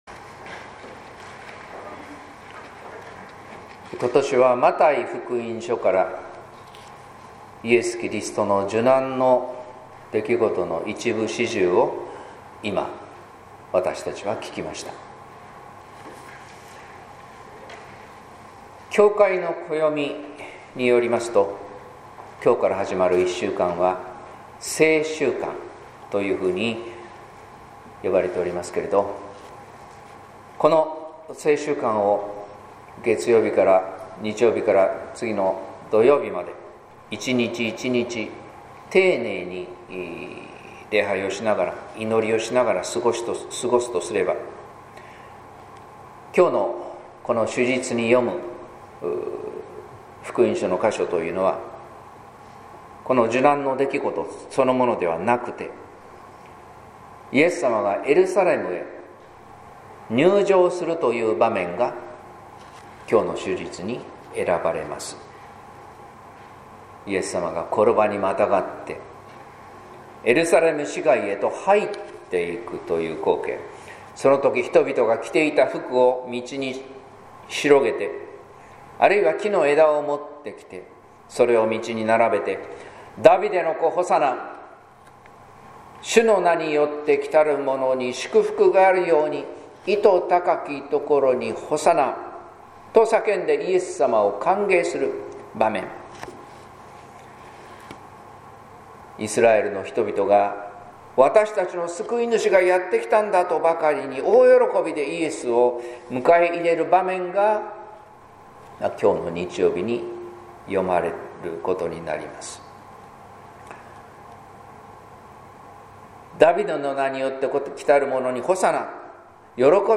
説教「スノードロップの涙」（音声版） | 日本福音ルーテル市ヶ谷教会